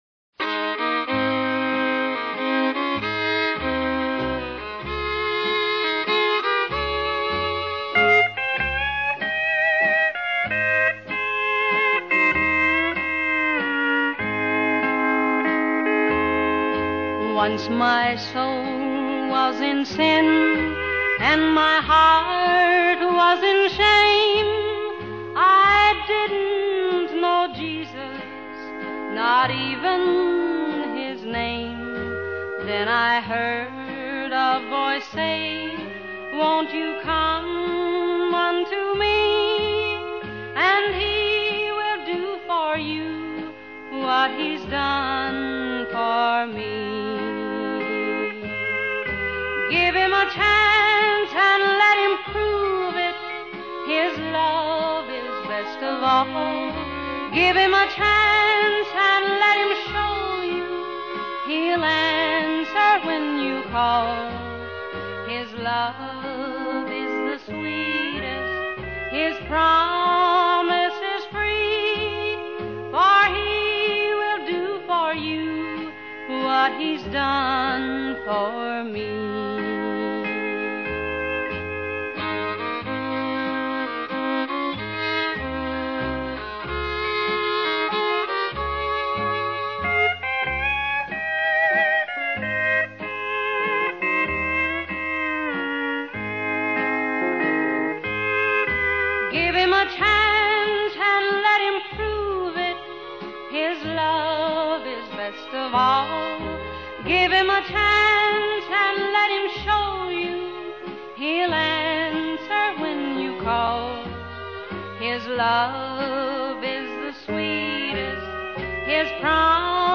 【老式鄉村歌曲】
Genre: Country | RAR 3% Rec.